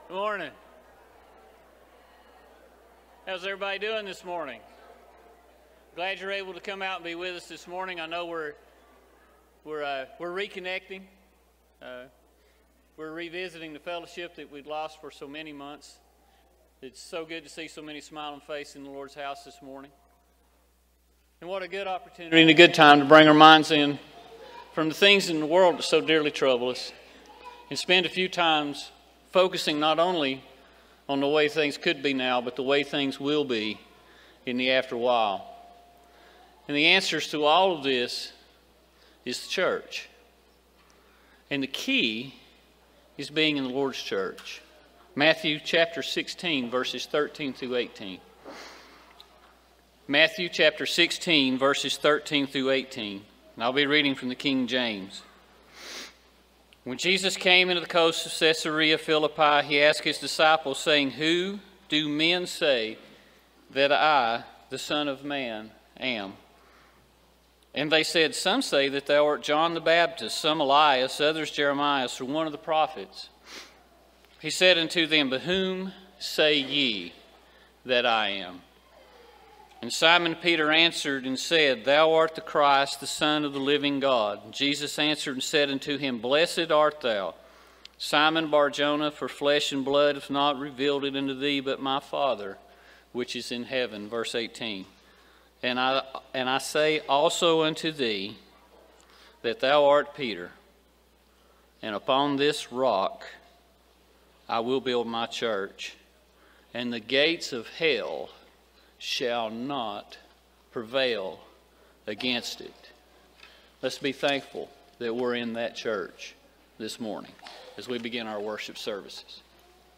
John 4:24, English Standard Version Series: Sunday AM Service